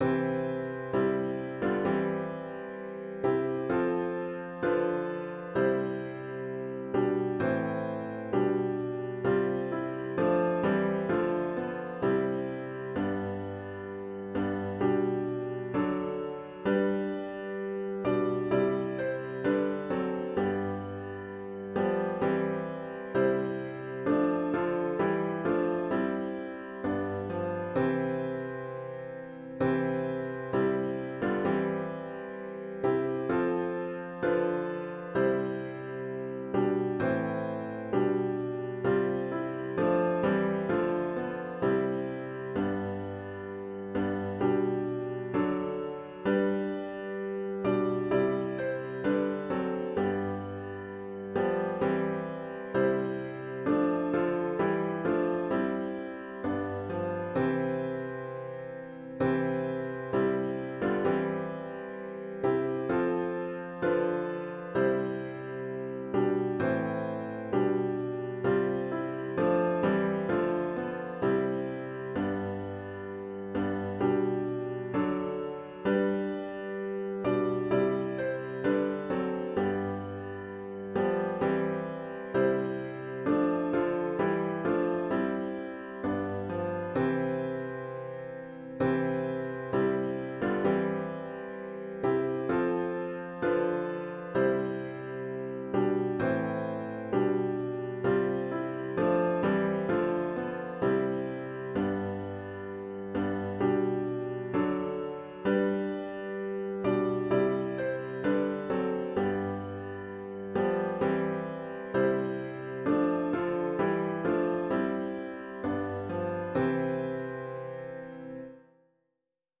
Sunday May 15, 2022 Worship Service
OPENING HYMN   “Christ Is Alive!”